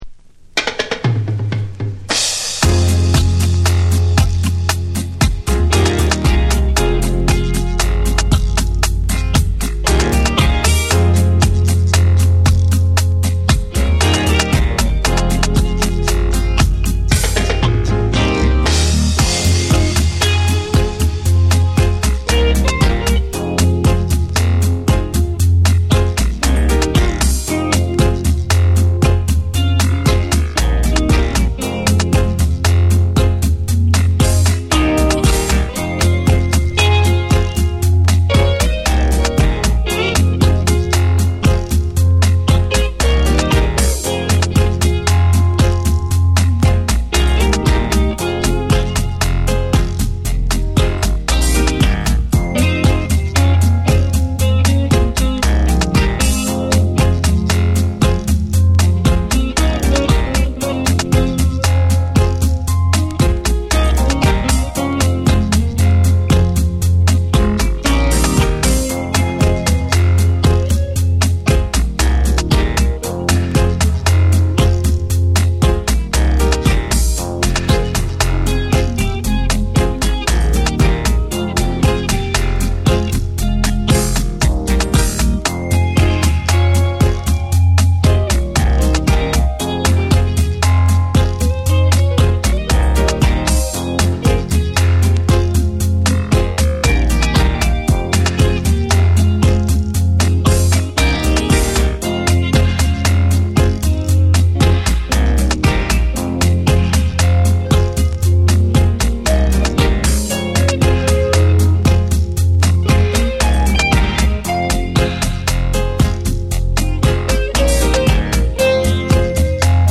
重厚なリディムに深く沈むベースと鋭いシンセが絡む
REGGAE & DUB